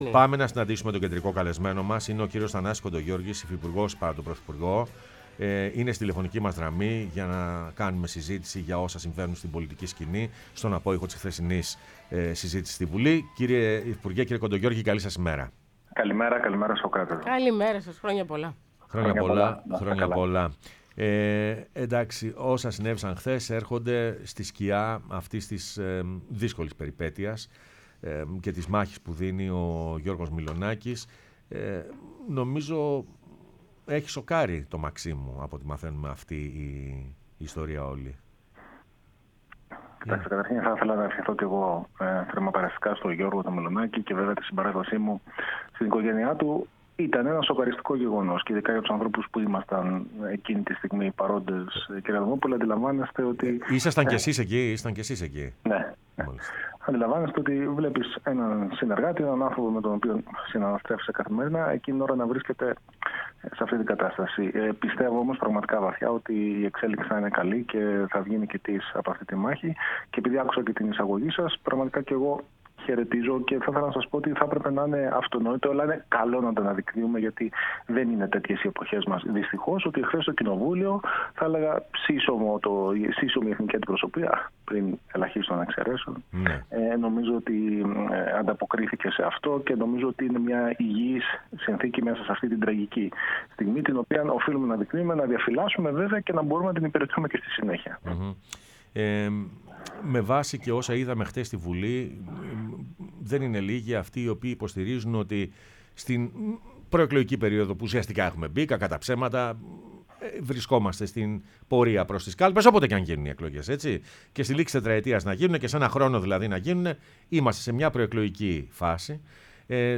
Ο Θανάσης Κοντογεώργης, Υφυπουργός παρά τω Πρωθυπουργώ, μίλησε στην εκπομπή «Πρωινές Διαδρομές»